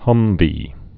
(hŭmvē)